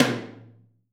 TOM 2H.wav